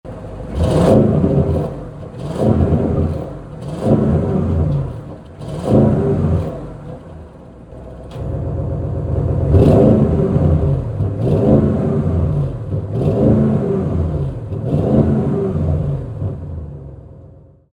2020 (70) Audi RS6 C8 (VORSPRUNG) Avant 4.0TFSI V8 Tiptronic Quattro 600PS | Daytona Grey |
VVC-revs.mp3